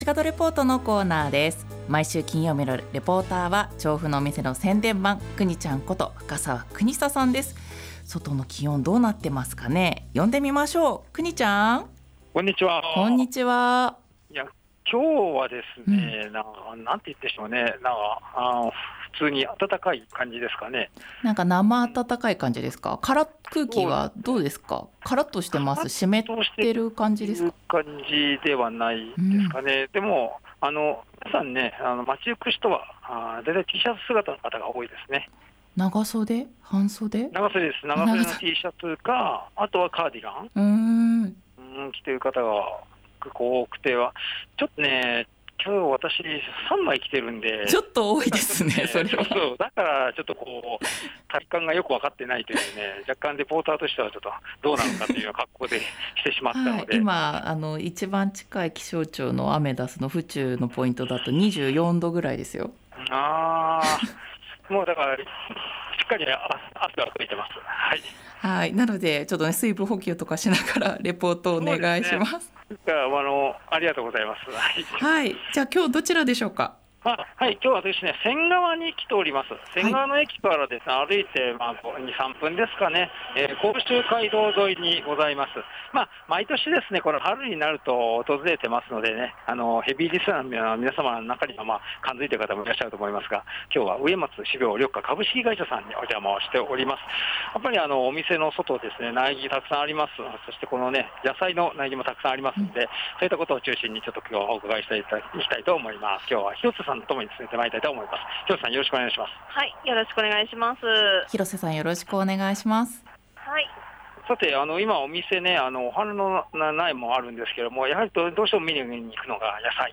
午後のカフェテラス 街角レポート
さて本日は仙川に出没です。 植松種苗緑化株式会社さんにお邪魔しました。